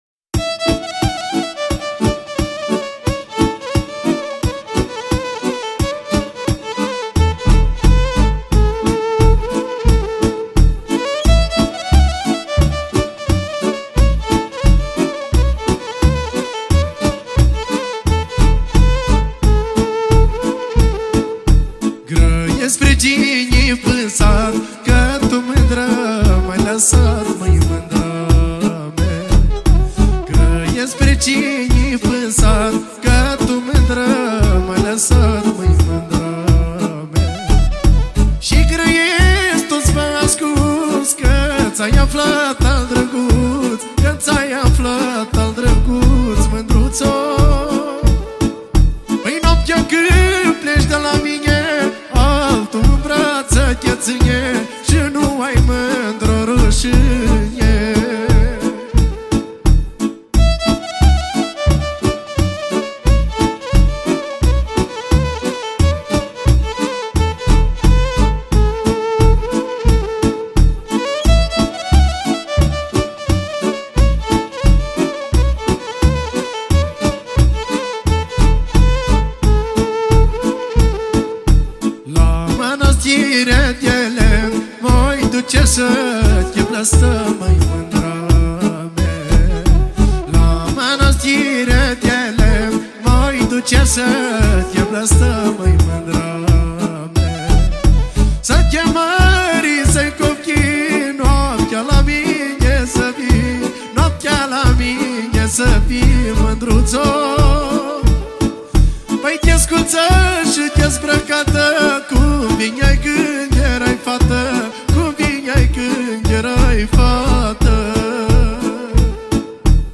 live De Maramures